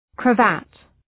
Shkrimi fonetik {krə’væt} ( emër ) ✦ kravetë ✦ shall